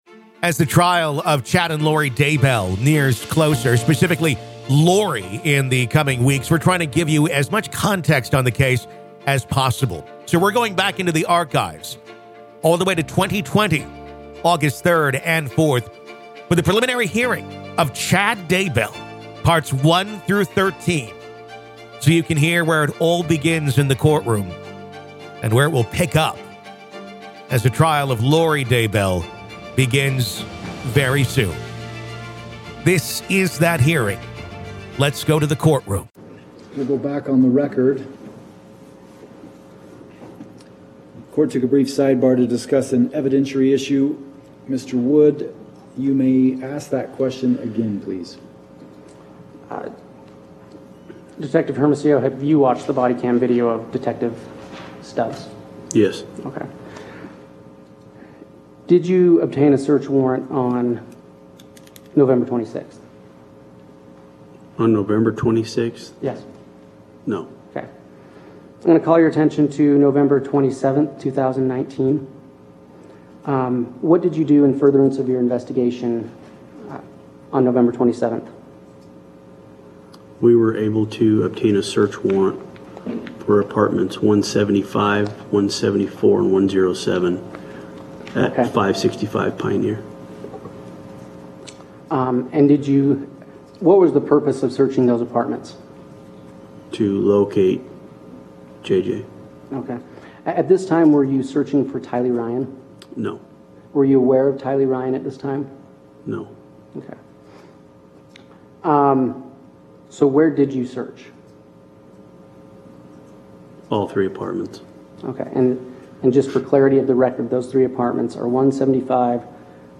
Listen To The Full Preliminary Hearing Of Chad Daybell, Part 2
This is the complete preliminary hearing of Chad Daybell, originally recorded August 3rd and 4th of 2020.